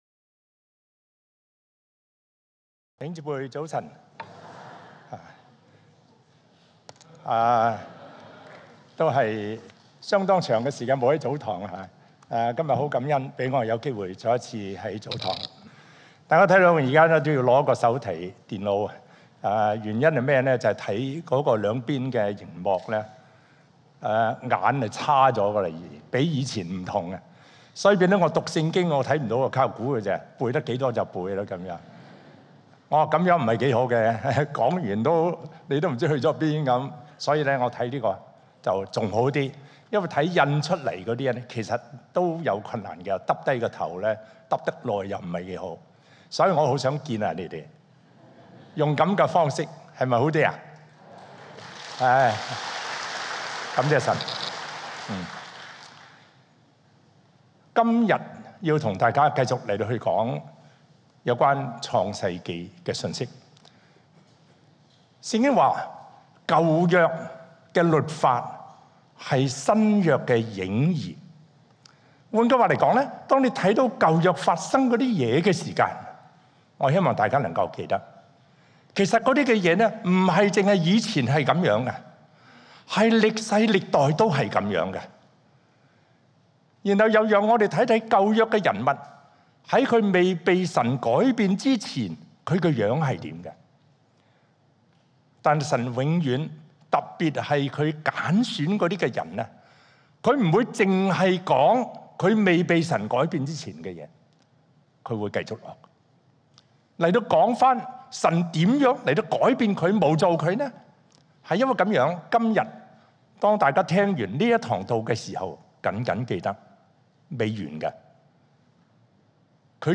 證道集
1.恩福早堂